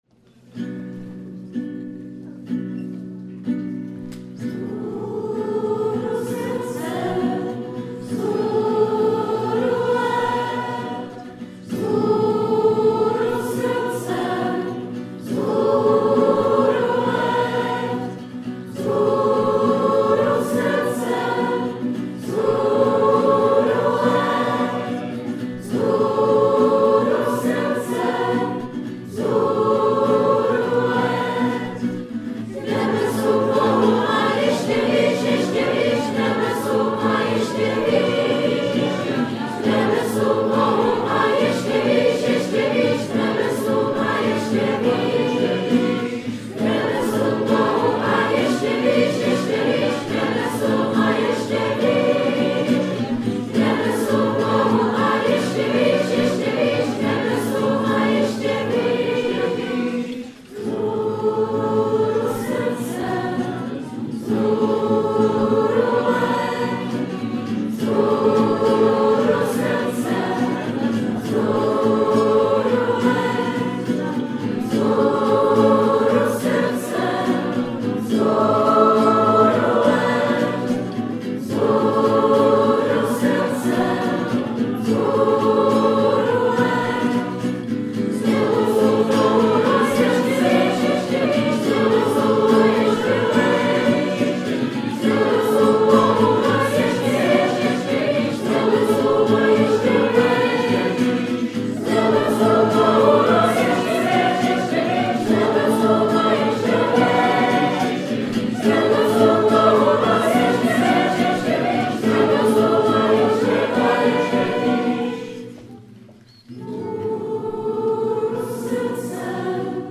VE�ER LIDSK�HO HLASU